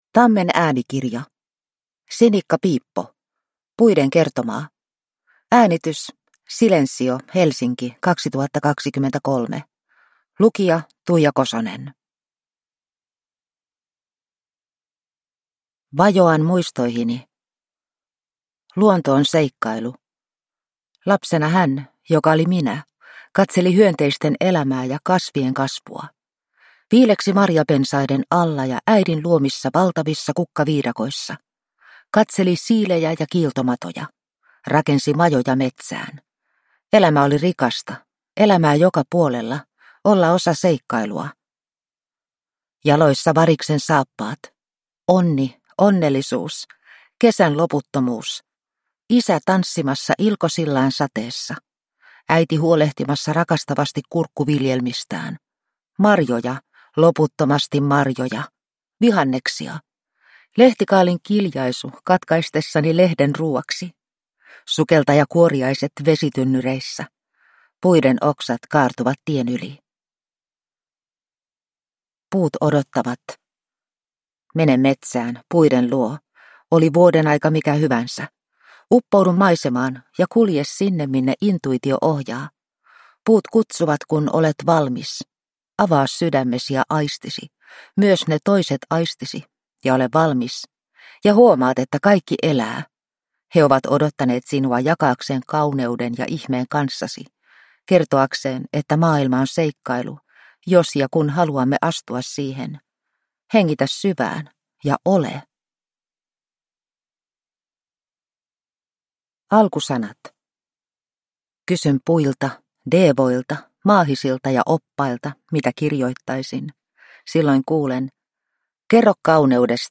Puiden kertomaa – Ljudbok – Laddas ner